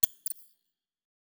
Sophisticated Bot Button Click.wav